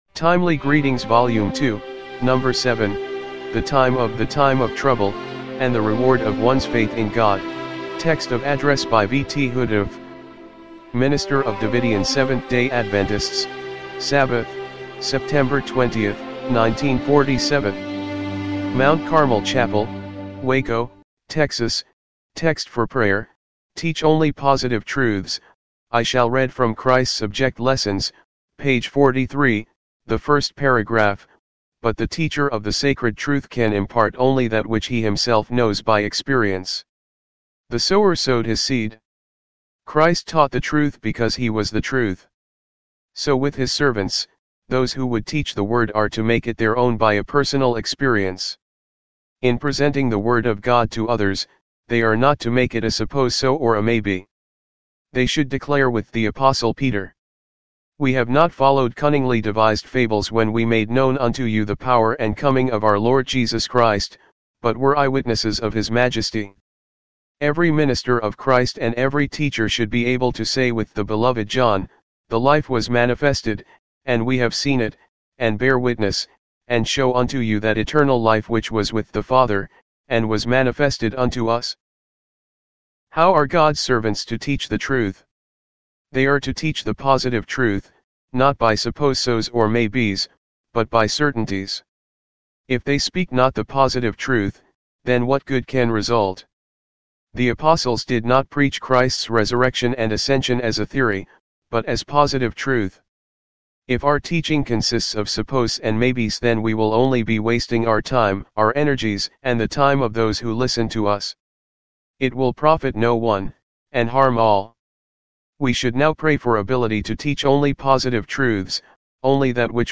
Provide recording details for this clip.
1947 MT. CARMEL CHAPEL WACO, TEXAS timely-greetings-volume-2-no.-7-mono-mp3.mp3